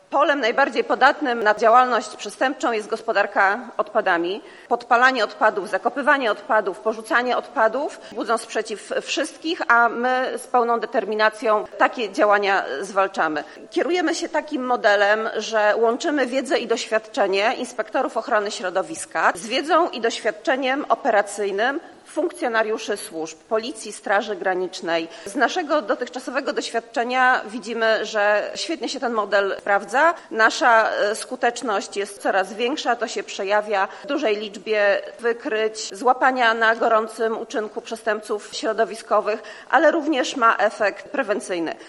W Zachodniopomorskim Urzędzie Wojewódzkim odbyła się inauguracja nowego Wydziału Zwalczania Przestępczości Środowiskowej w strukturach Wojewódzkiego Inspektoratu Ochrony Środowiska w Szczecinie.
Łączymy wiedzę i doświadczenie inspektorów ochrony środowiska z wiedzą i doświadczeniem operacyjnym służb – mówi p.o. Głównego Inspektora Ochrony Środowiska Magda Gosk